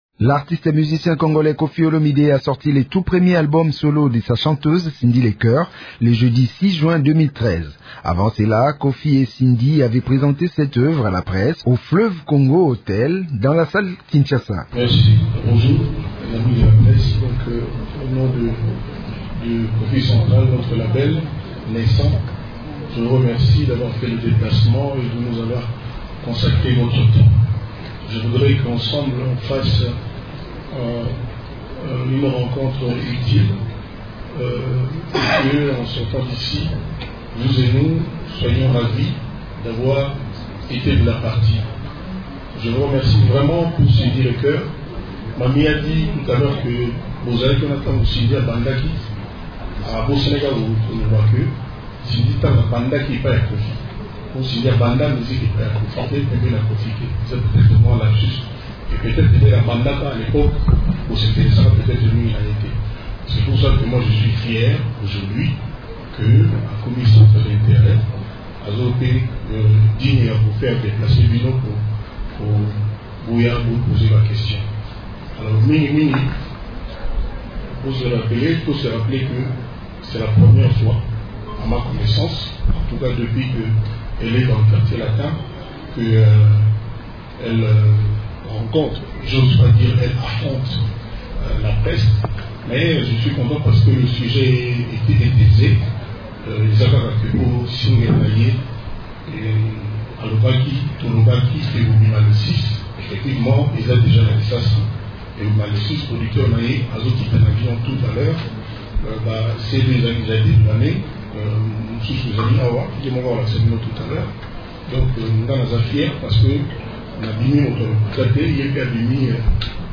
Ecouter l’intégralité de la présentation de cet album.